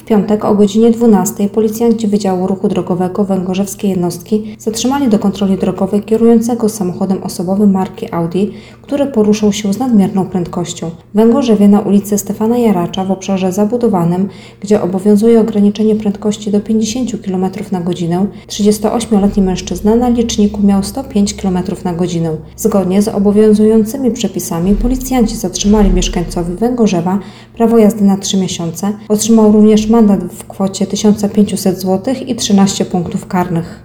Mówiła Radiu 5